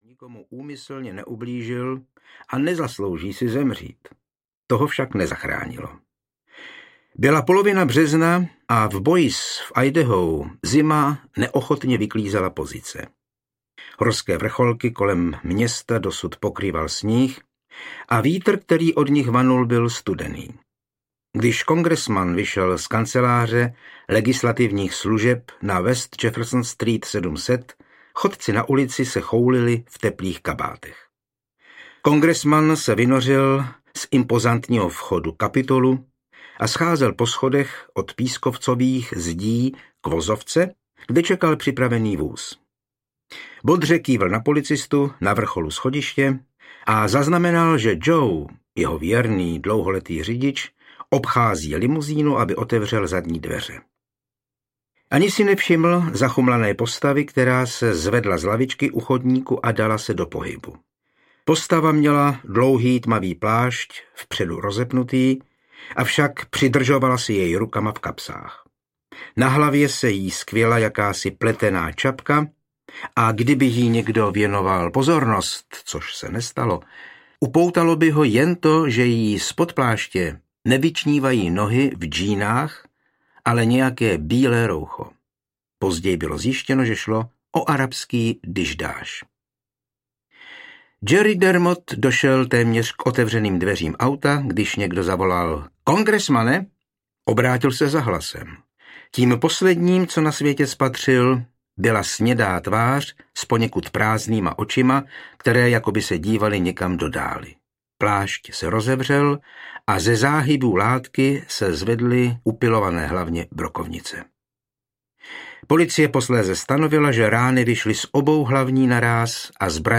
Seznam smrti audiokniha
Ukázka z knihy